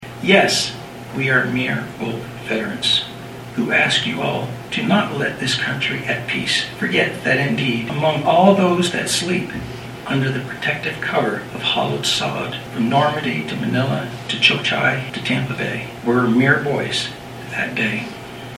read a short poem called Mere Boys. The poem referred to those going into war as “mere boys,” and the sacrifices they made for peace.
The ceremony, which was moved indoors due to rain Monday, honored all the fallen soldiers from all of the wars throughout this country’s history.